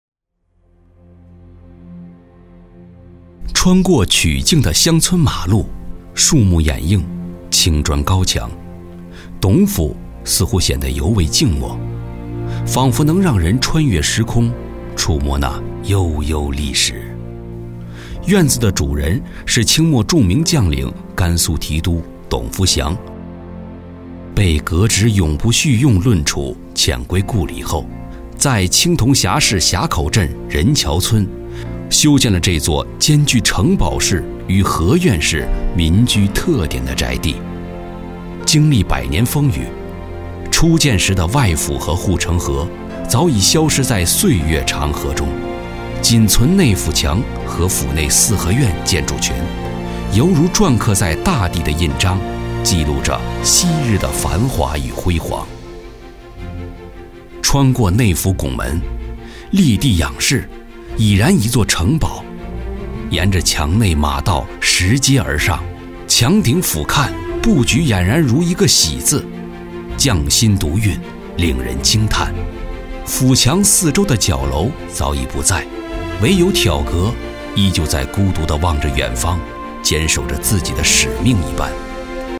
100%人工配，价格公道，配音业务欢迎联系：
B男199号
【纪录片】董府砖木结缘
【纪录片】董府砖木结缘.mp3